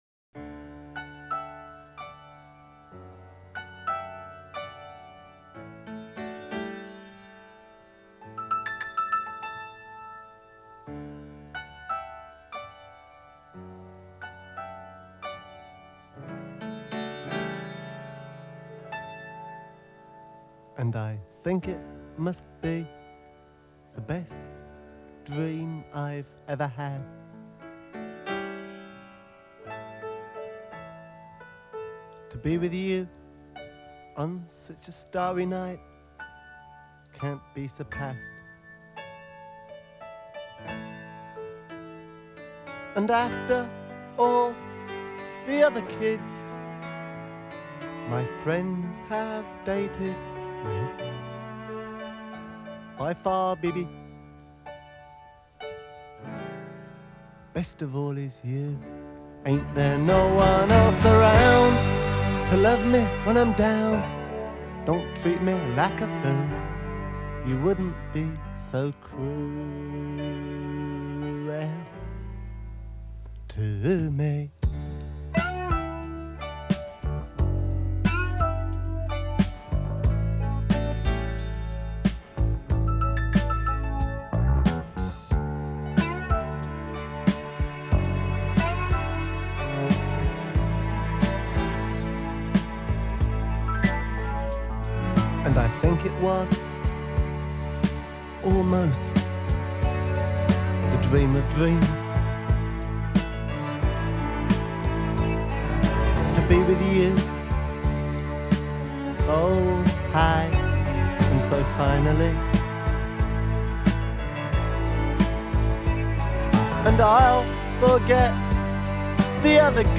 backing vocals
bass
drums
fiddle
guitar
keyboards